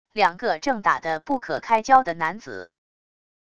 两个正打得不可开交的男子wav音频